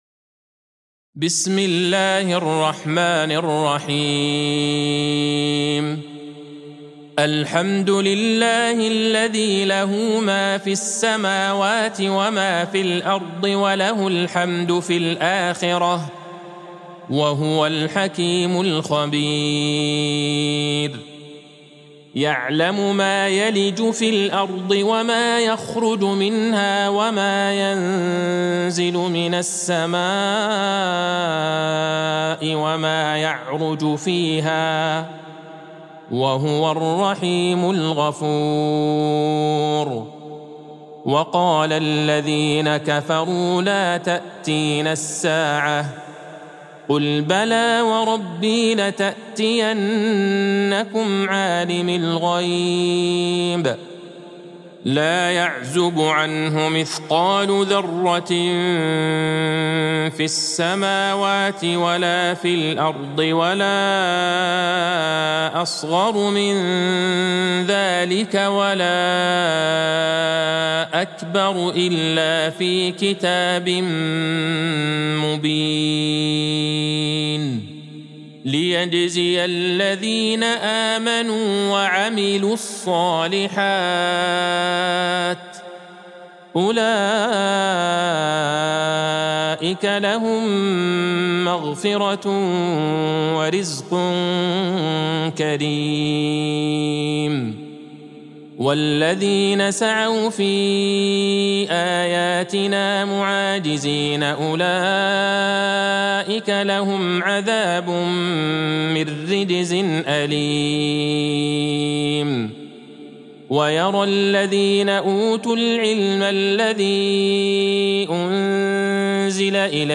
سورة سبأ Surat Saba | مصحف المقارئ القرآنية > الختمة المرتلة